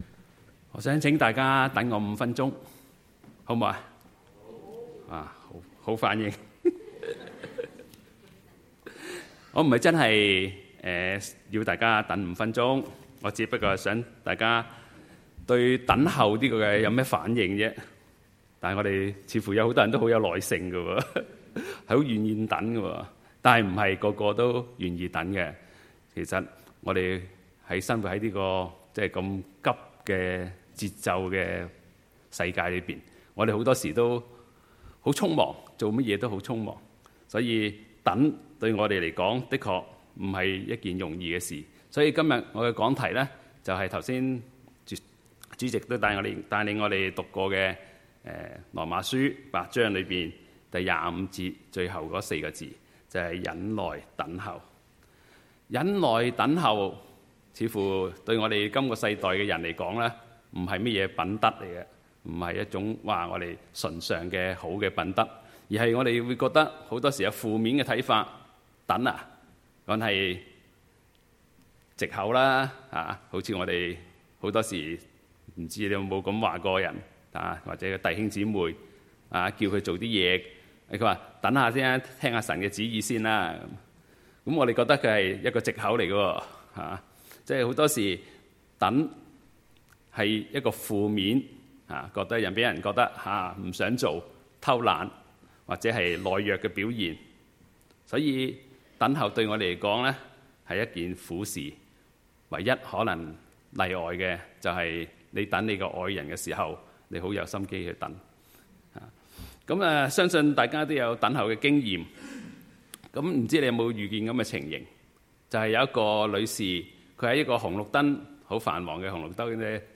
Sermons | Fraser Lands Church 菲沙崙教會